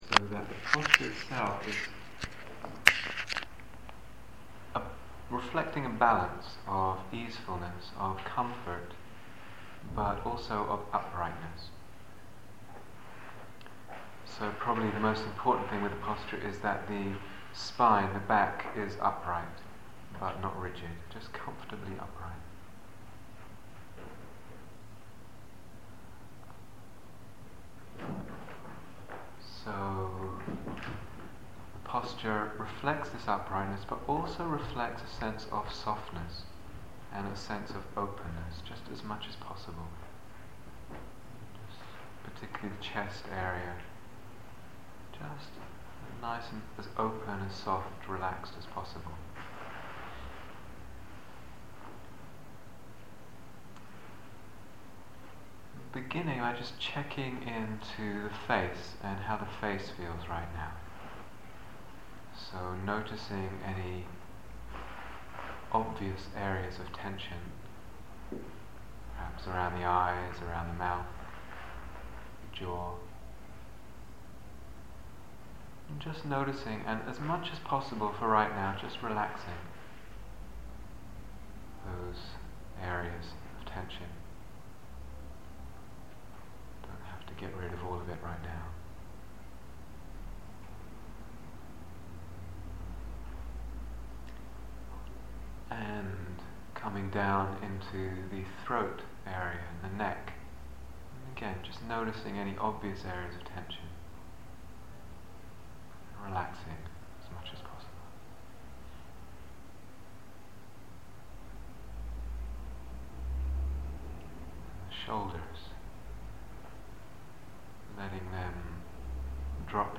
Guided Meditation
Cambridge Day Retreats 2007